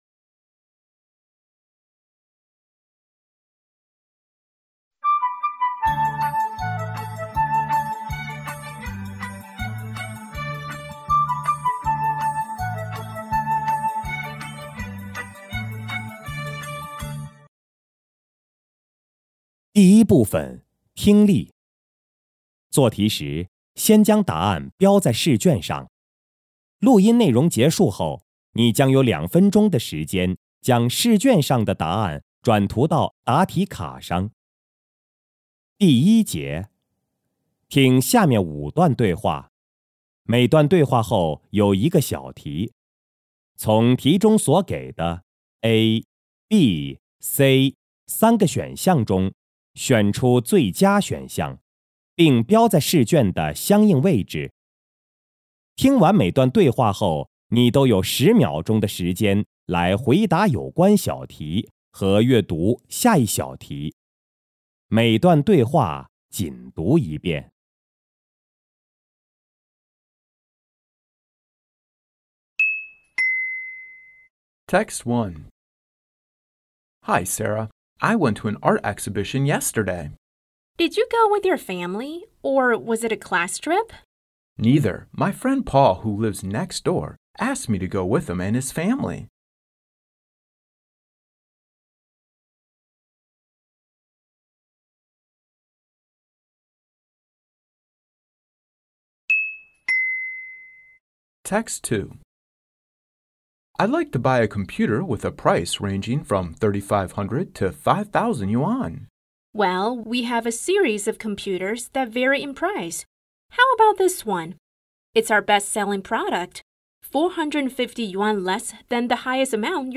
四川省百师联盟2026届高三一轮复习第一次调研考试英语听力.mp3